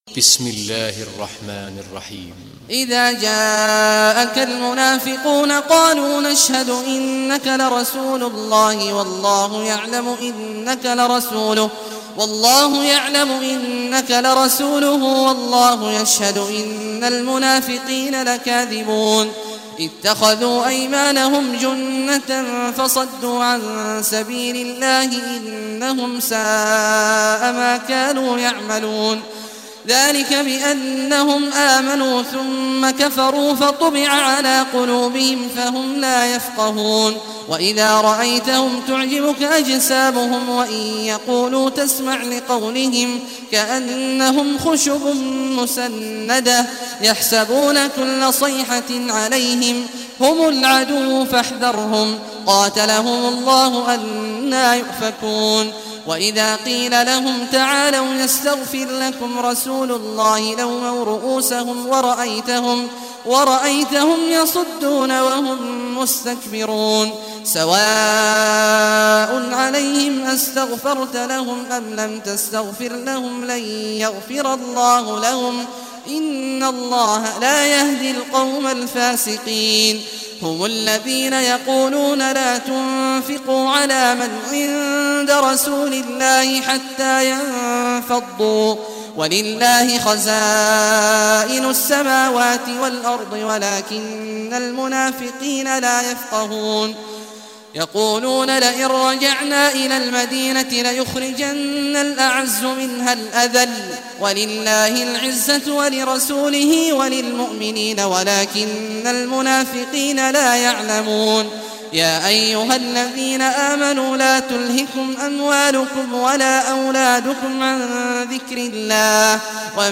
Surah al-Munafiqun Recitation by Sheikh Al Juhany
Surah al-Munafiqun, listen or play online mp3 tilawat / recitation in Arabic in the beautiful voice of Sheikh Abdullah Awad al Juhany.